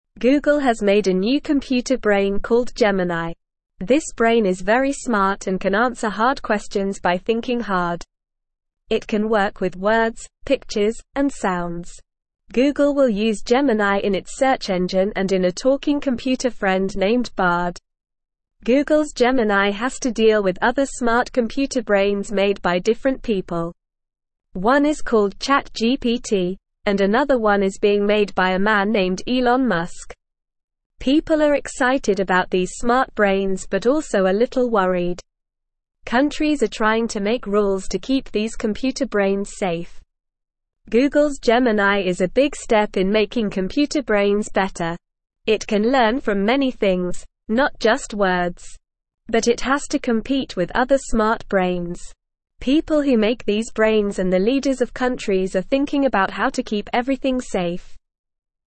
Normal
English-Newsroom-Lower-Intermediate-NORMAL-Reading-Googles-Smart-Computer-Friend-Gemini-Can-Answer-Questions.mp3